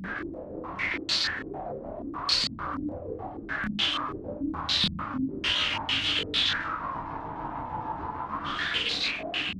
STK_MovingNoiseA-100_03.wav